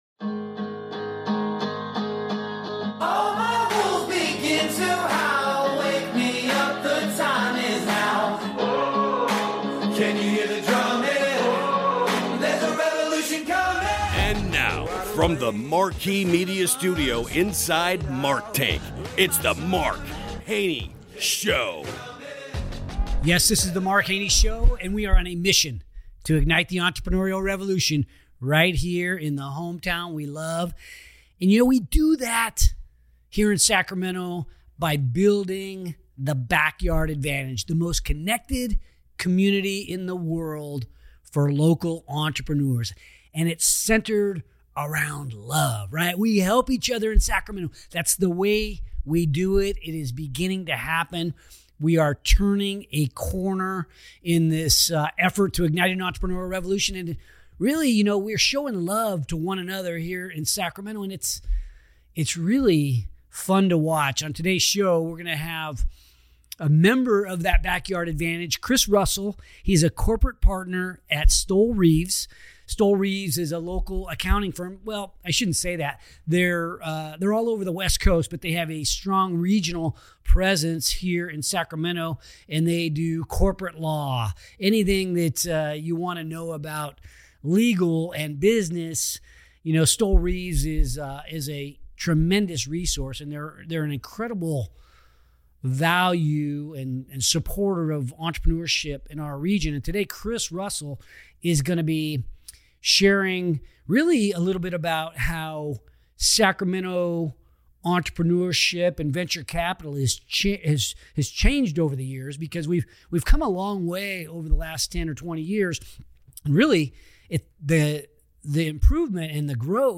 Fostering Innovation in Sacramento: A Conversation